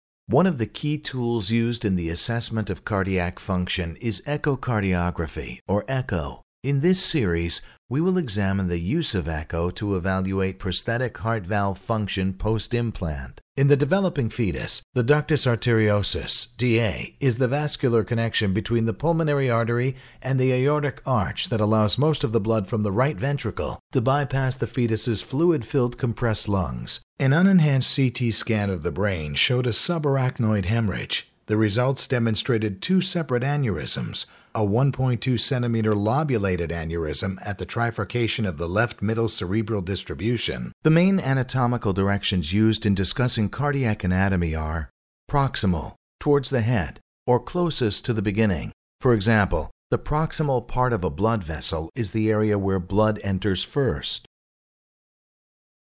compelling, dynamic, velvety, contemplative, friendly, sexy, romantic, buttery, adaptable and easy to work with
englisch (us)
Sprechprobe: eLearning (Muttersprache):